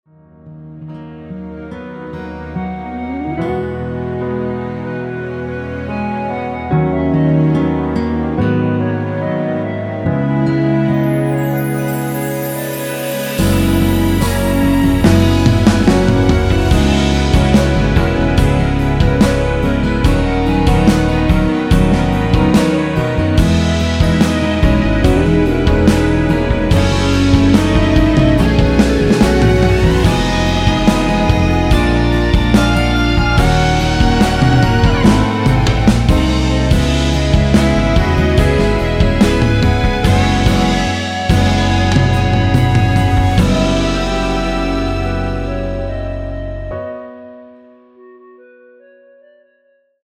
노래가 바로 시작 하는 곡이라 전주 만들어 놓았으며
원키에서(-1)내린 멜로디 포함된 1절후 후렴으로 진행되게 편곡한 MR 입니다.(미리듣기및 가사 참조)
앞부분30초, 뒷부분30초씩 편집해서 올려 드리고 있습니다.
중간에 음이 끈어지고 다시 나오는 이유는